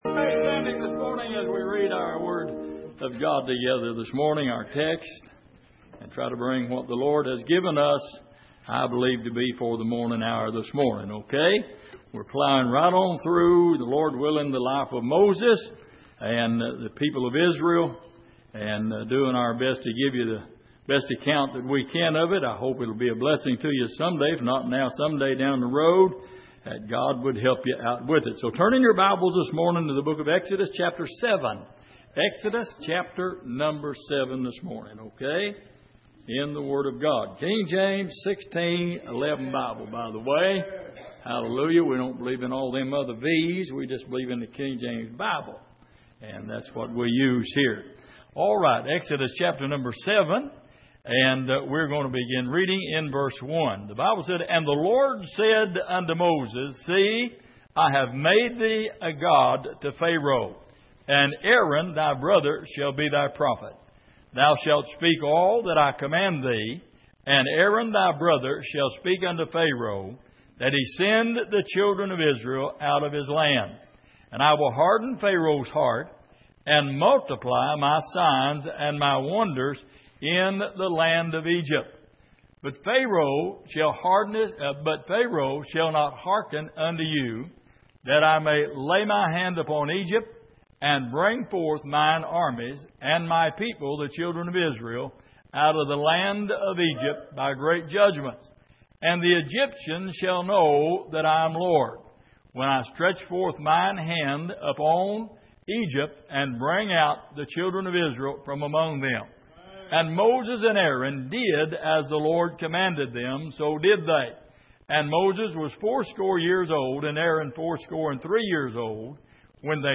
The Life of Moses Passage: Exodus 7:1-14 Service: Sunday Morning The Lord